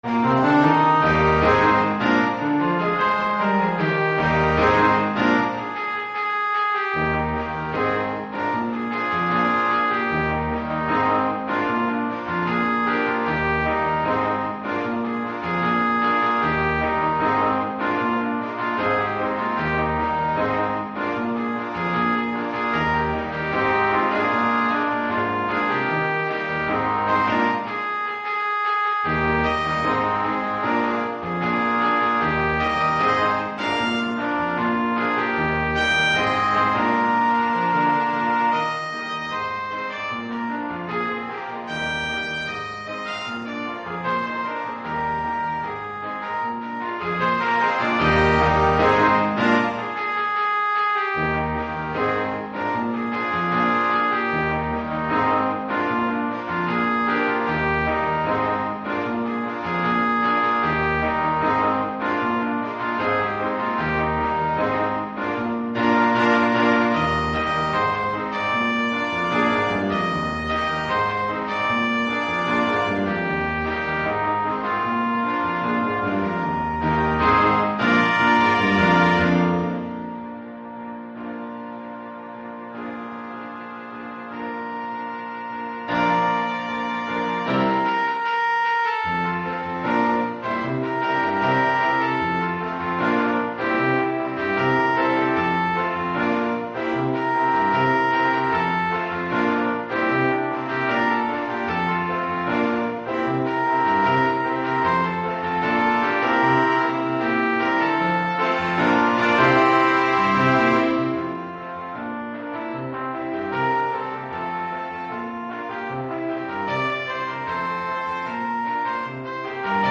Keyboard & Drums optional.
Gattung: 5-Part Ensemble
Besetzung: Ensemble gemischt PDF